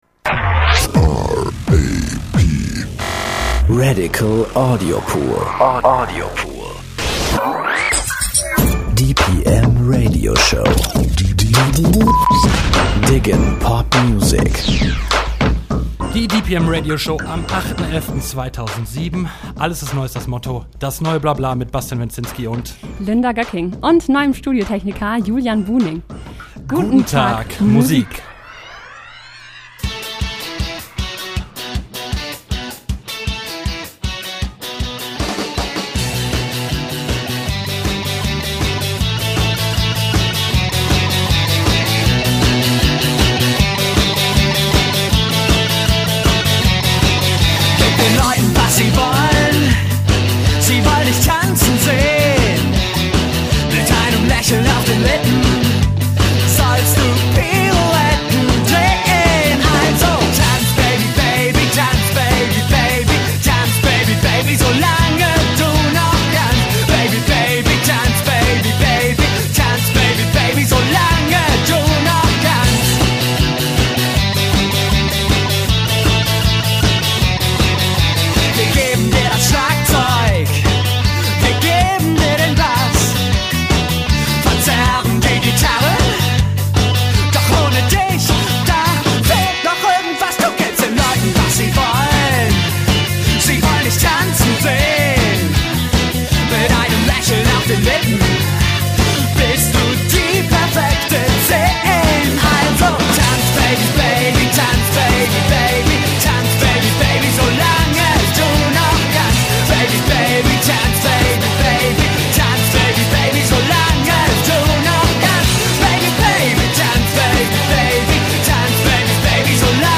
Audiobeitrag über das Buddy-Programm